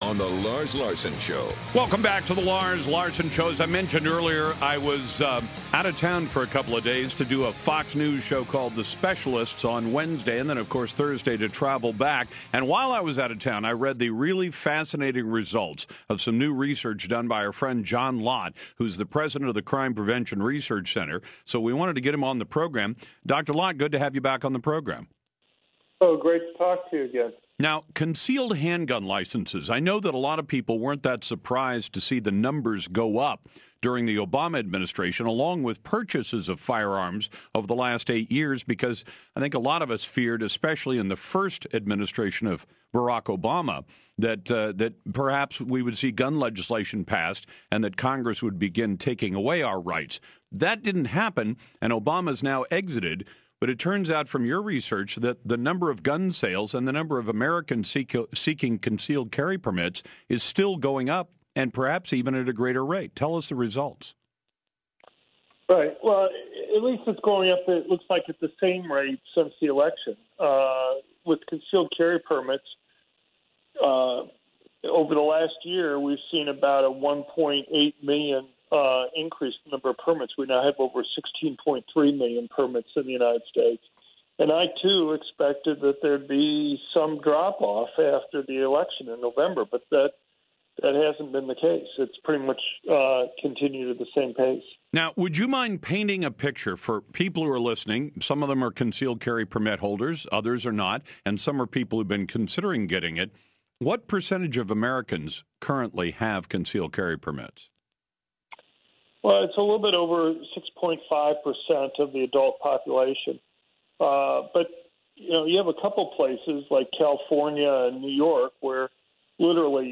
media appearance
Dr. John Lott talked to Lars Larson about the CPRC’s newest report on concealed handgun permits.  The report shows a dramatic increase in the number of permit holders as well as the changing demographics of permit holders and how law-abiding they are.